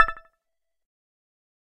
click.ogg